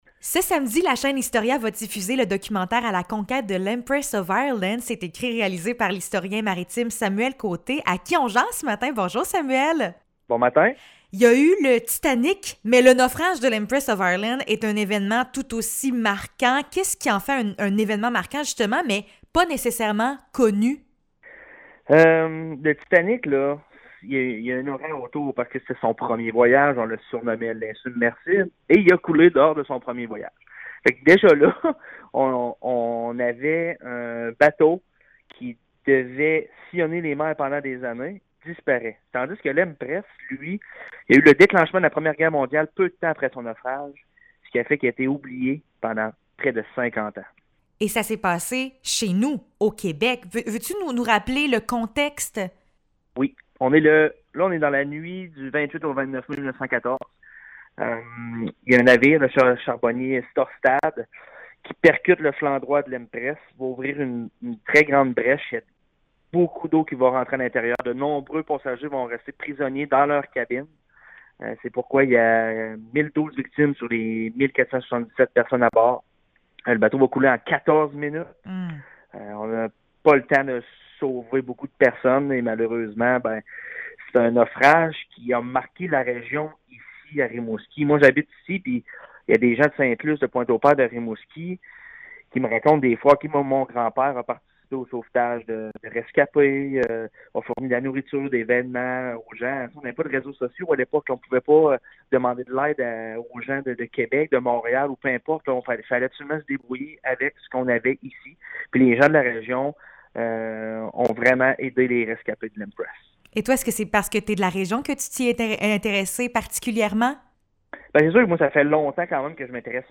Entrevue sur l’Empress of Ireland (27 mai 2022)